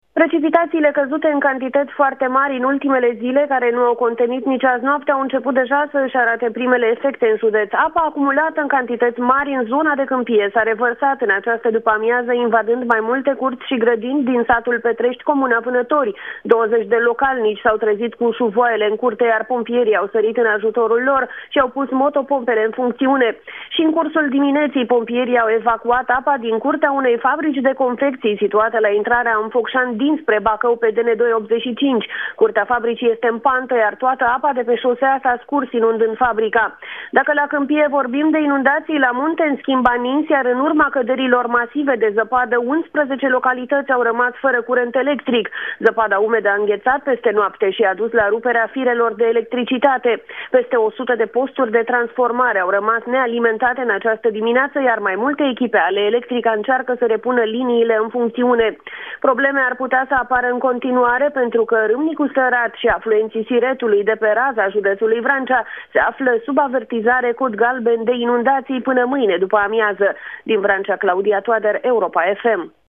O corespondență